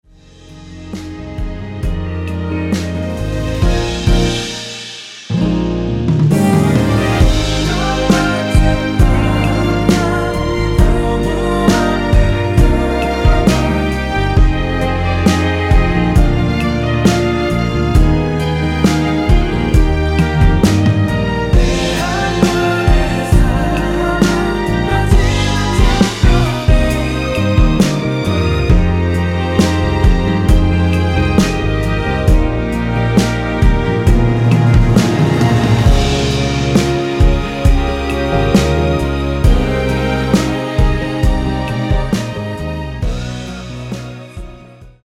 원키 코러스 포함된 MR입니다.
Eb
앞부분30초, 뒷부분30초씩 편집해서 올려 드리고 있습니다.